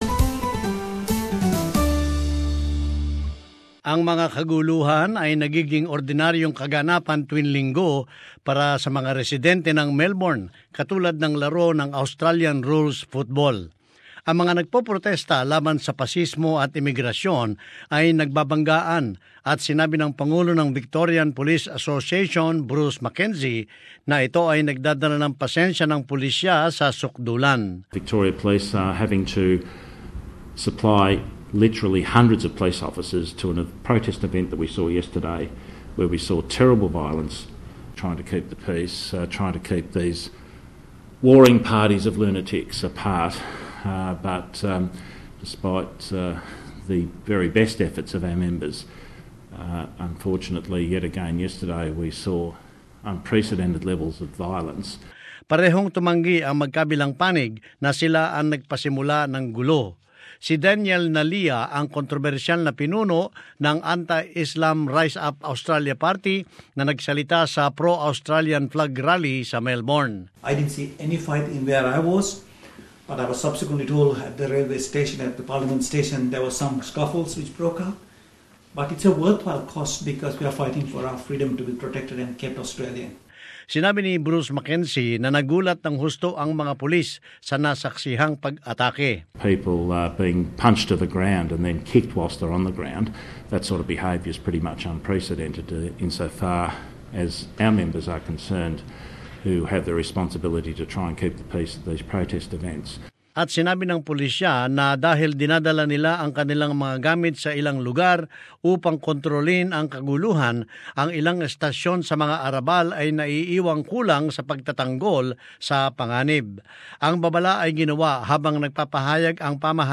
And as this report shows, the Police Association says it is dragging resources away from vulnerable areas.